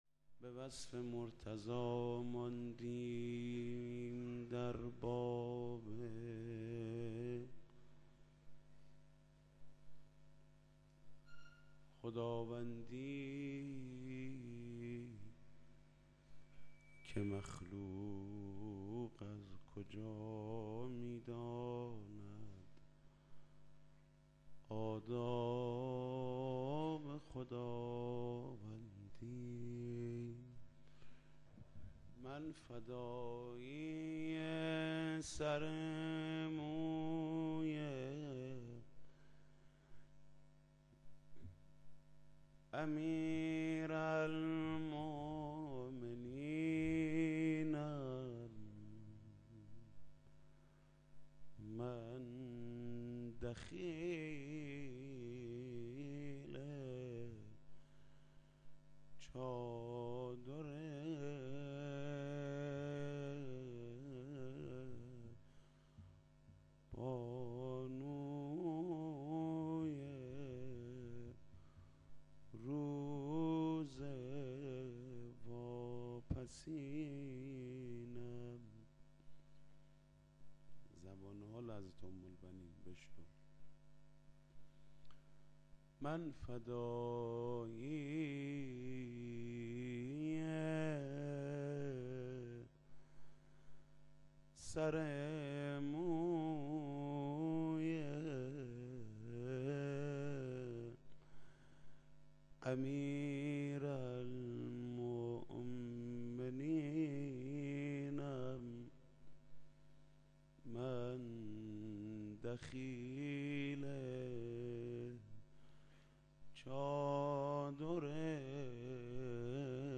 حاج محود کریمی -روضه ام البنین- قسمت اول-قزوین-آستان مقدس چهارانبیا-موسسه پرچمدار.mp3